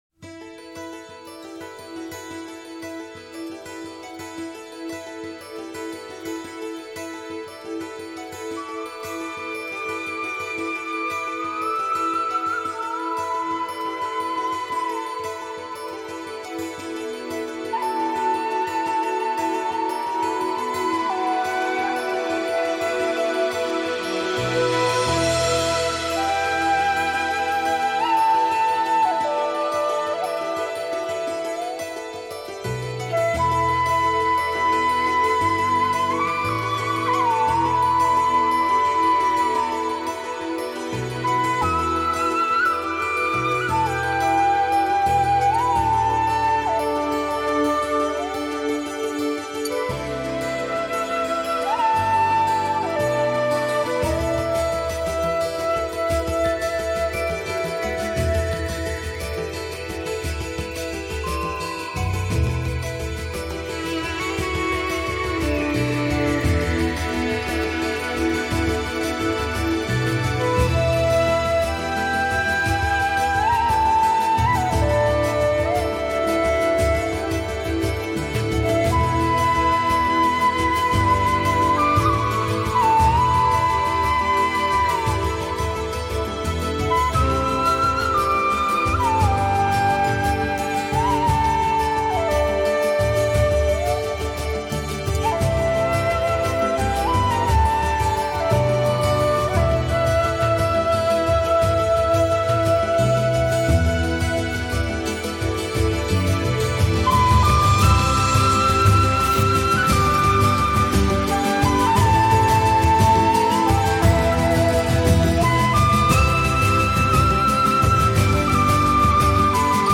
这张专辑的多数曲目都有一种交响乐的风格。